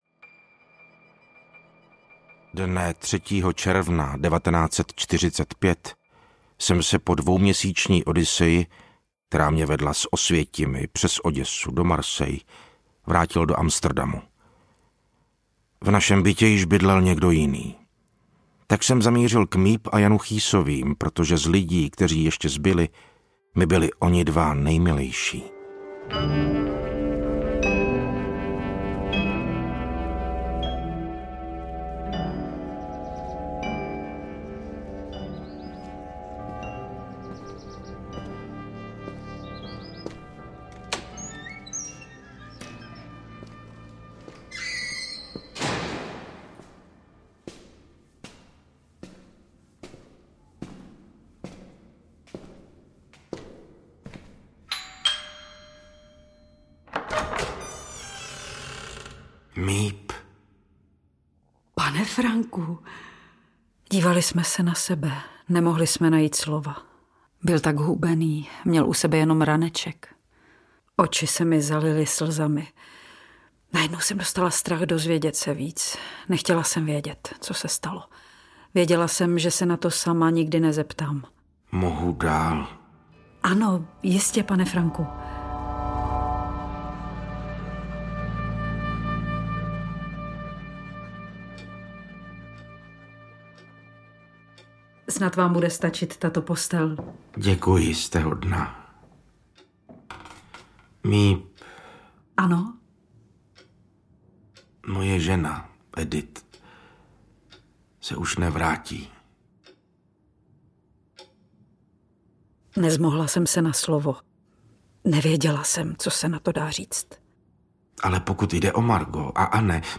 Deník Anne Frankové audiokniha
Ukázka z knihy